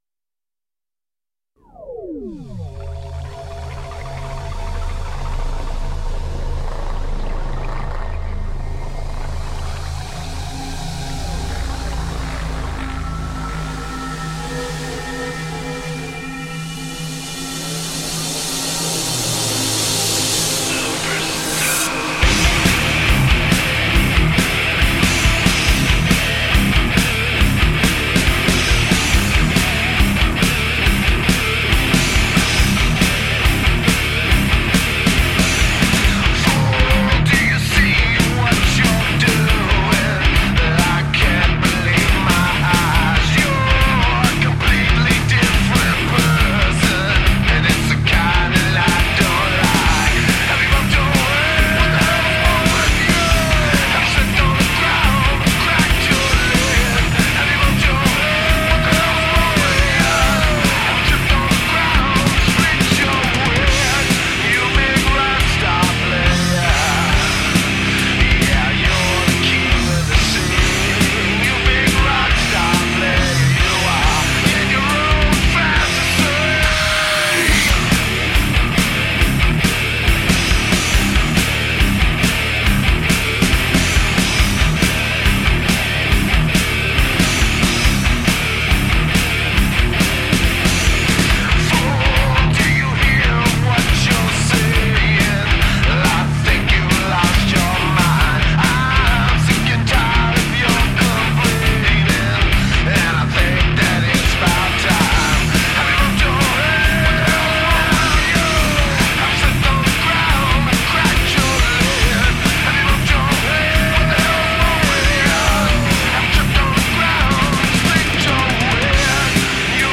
Heavy metal with strong melodies and big riffs.
Tagged as: Hard Rock, Other, Intense Metal